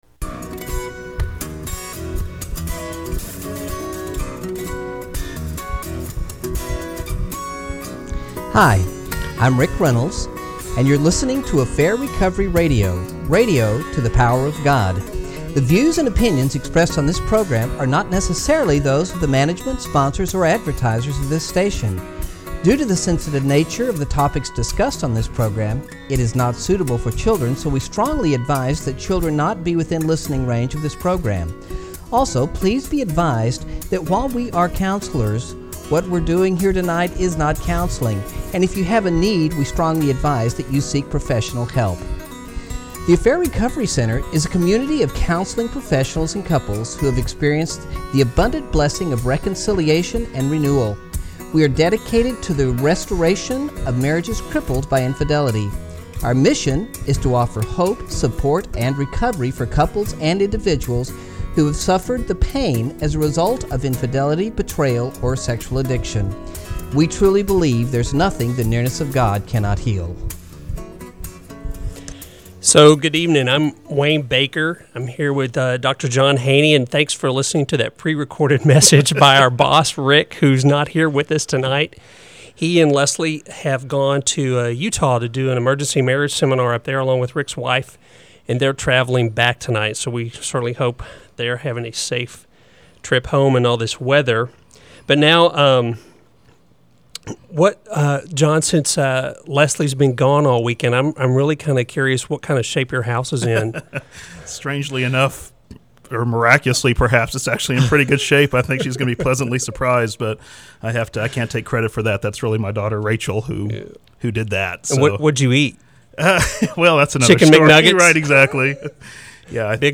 They also take a few calls and touch on other topics related to sexuality.